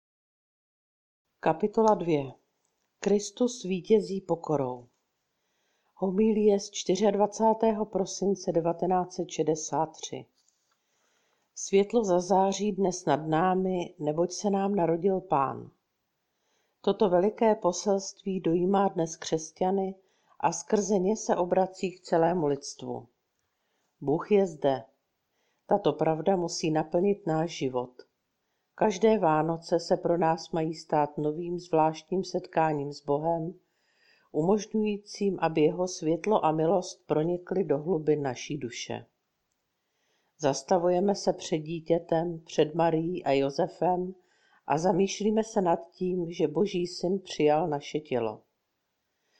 Ukázka z nově načtené knihy Jít s Kristem – sebrané promluvy sv. Mons. Escrivy k nejdůležitějším svátkům církevního roku.